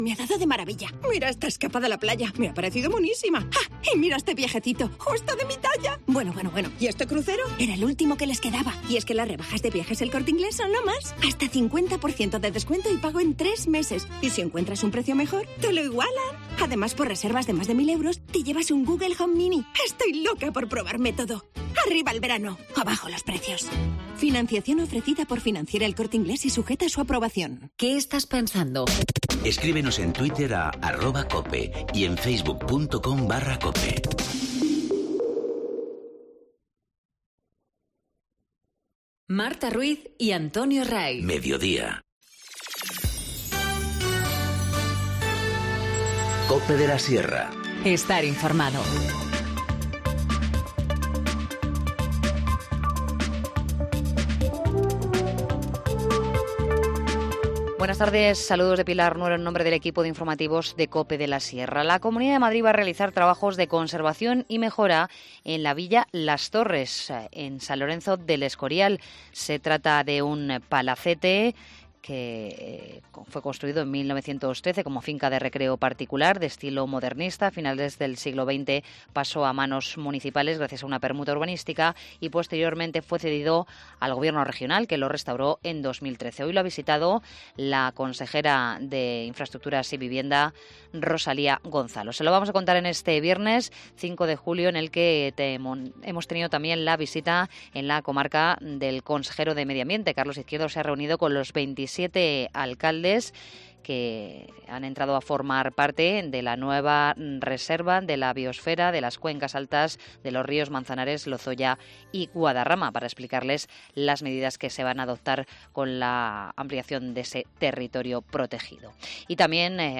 Informativo Mediodía 5 julio 14:20h